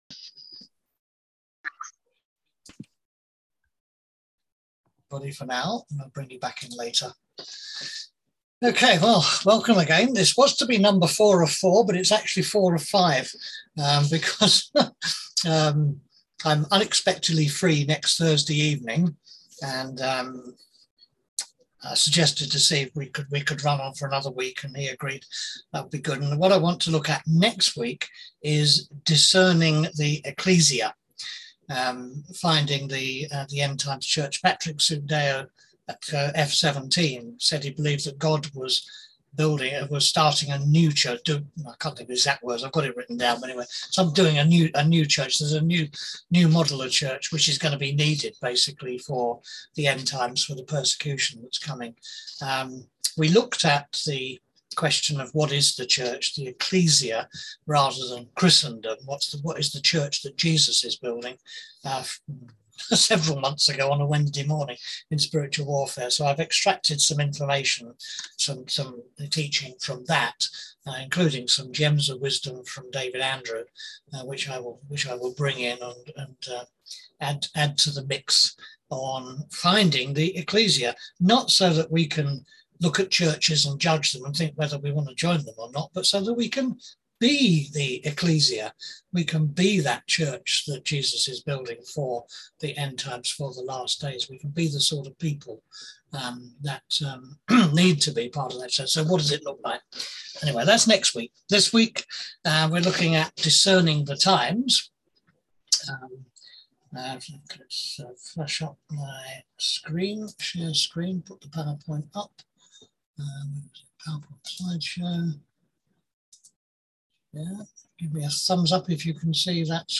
On September 1st at 7pm – 8:30pm on ZOOM